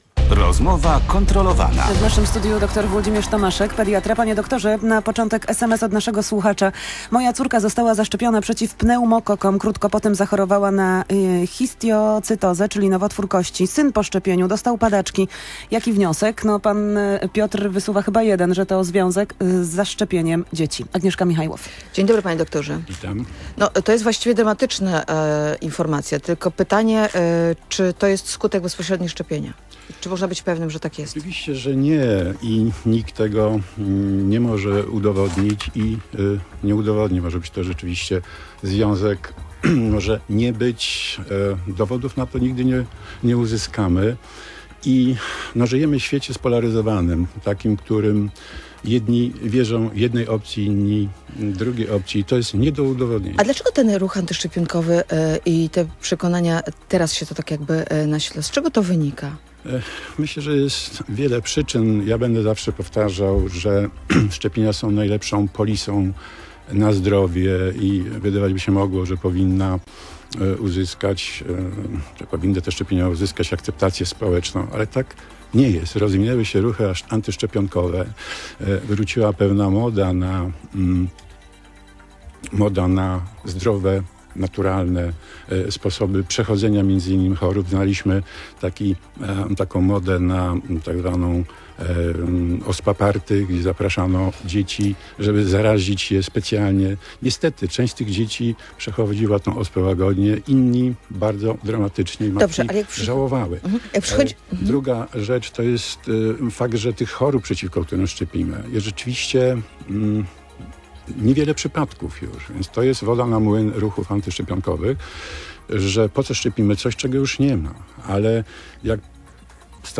Tematem rozmowy były szczepienia dzieci.